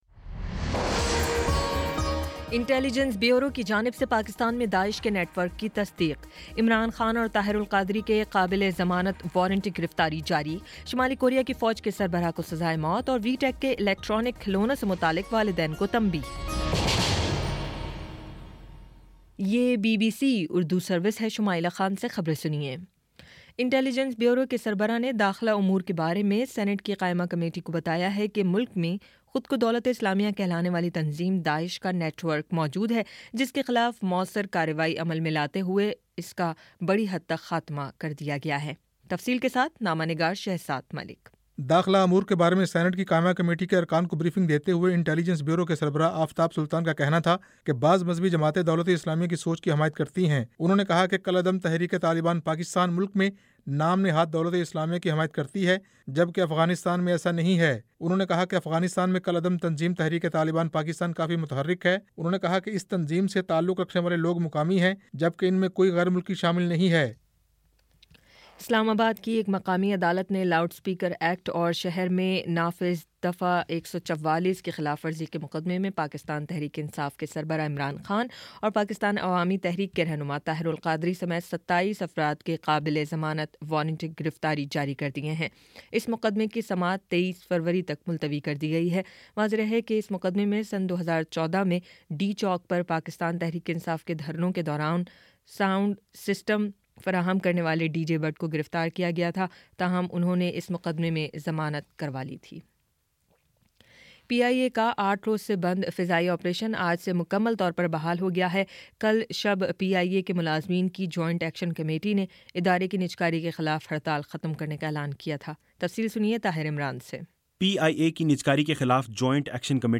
فروری 10 : شام چھ بجے کا نیوز بُلیٹن